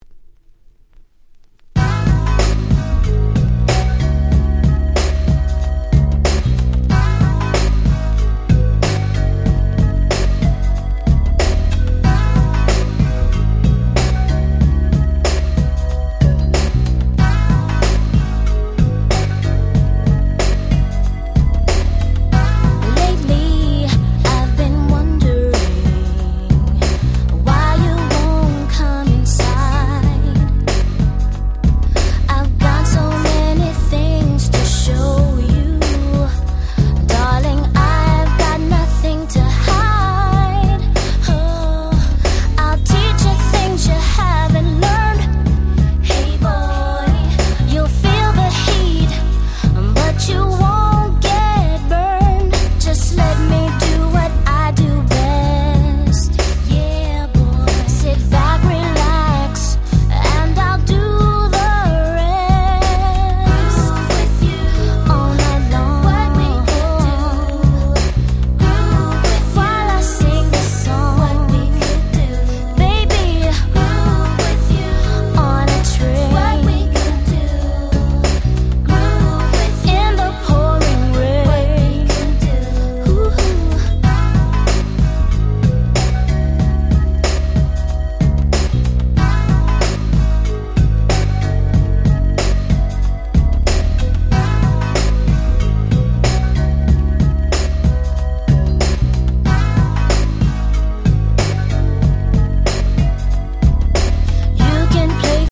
94' Very Nice Hip Hop Soul / R&B !!
New York発の黒人女性2人組。